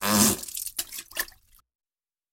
Звук стремительного вытекания